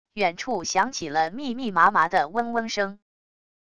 远处响起了密密麻麻的嗡嗡声wav音频